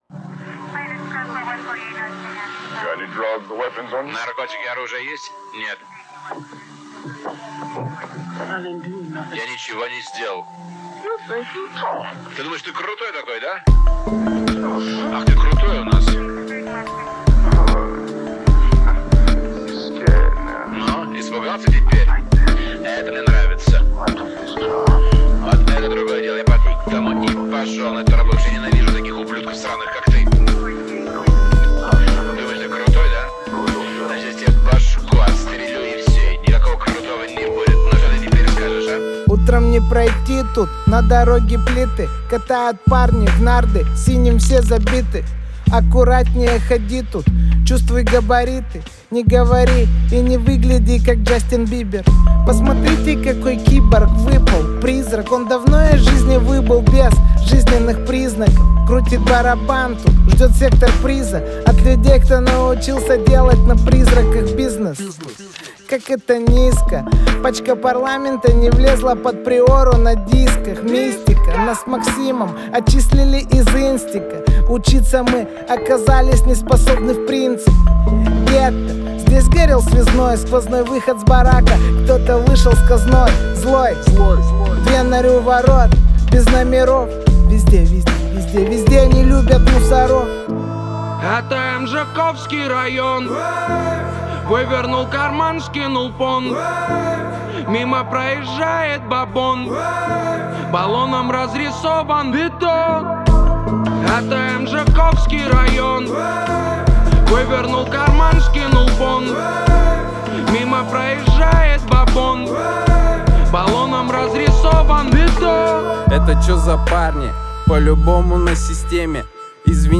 Назад в (рэп)...